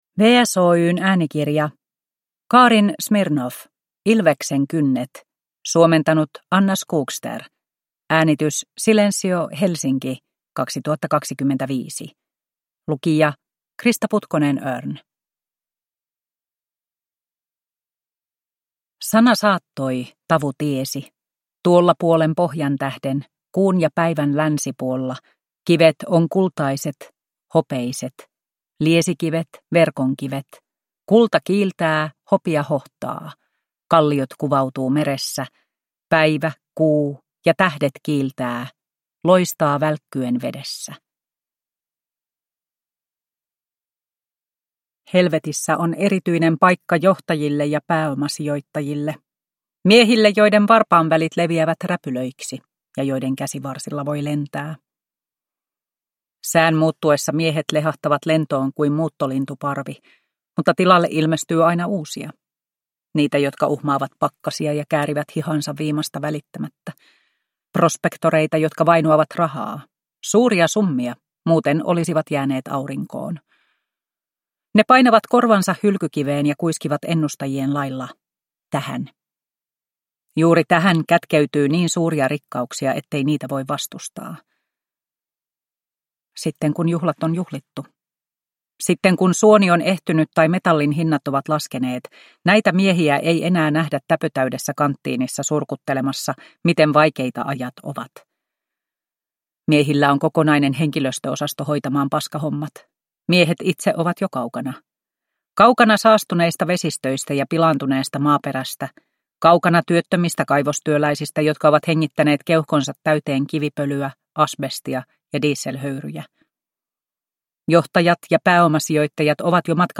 Ilveksen kynnet (ljudbok) av Karin Smirnoff